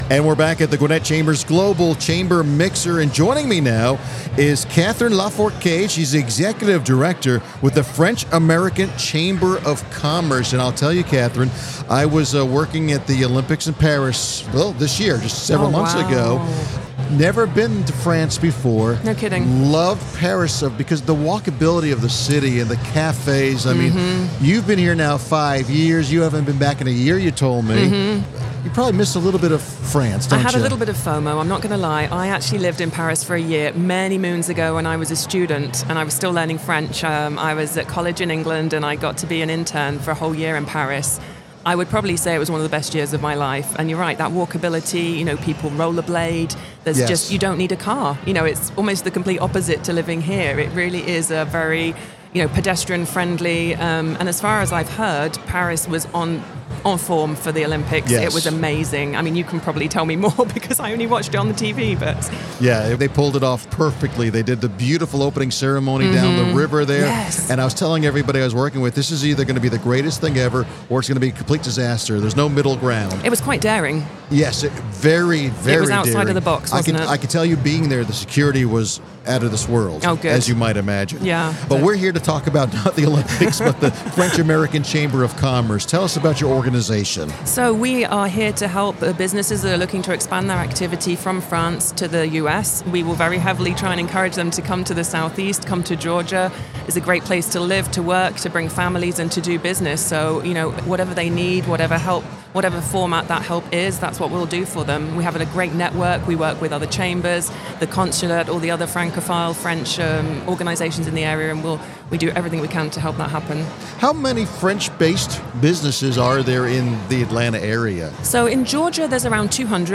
The Gwinnett Chamber of Commerce hosted it’s 2024 Global Chamber Mixer on November 7 at the Gas South Convention Center in Duluth, GA.